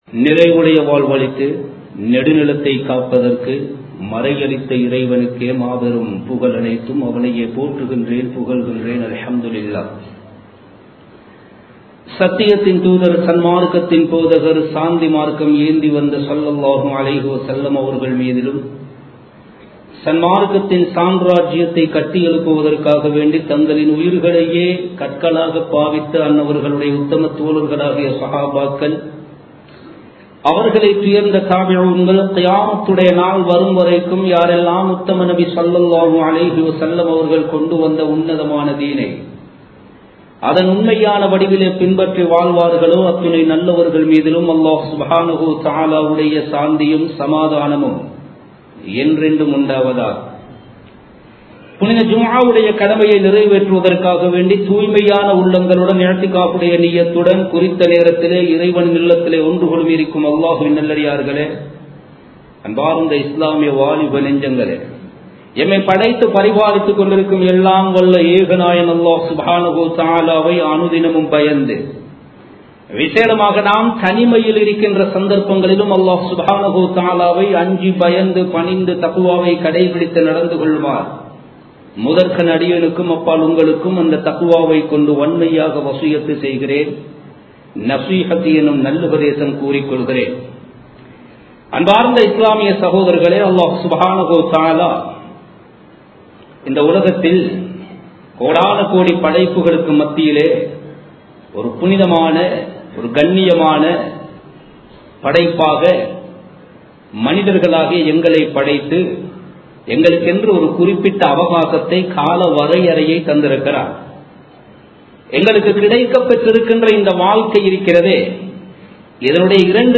Thanimaium Thaqwaum (தனிமையும் தக்வாவும்) | Audio Bayans | All Ceylon Muslim Youth Community | Addalaichenai